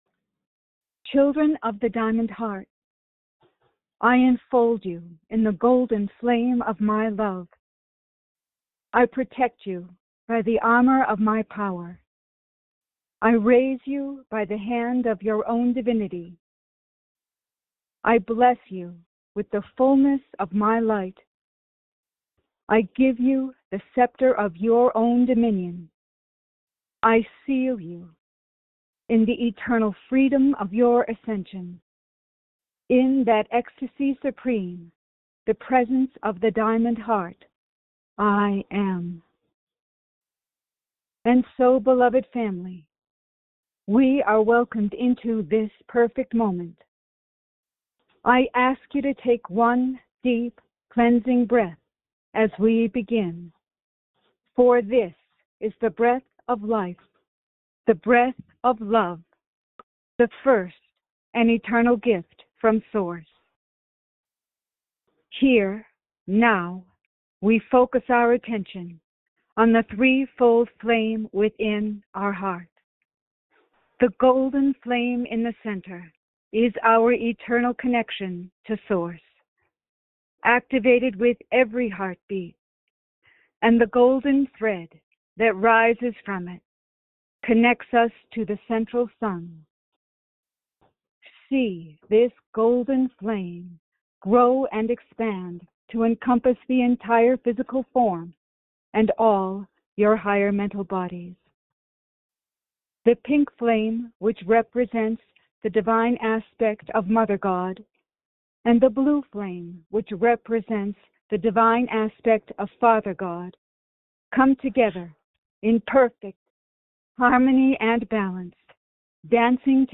Join in group meditation with Lord Sananda.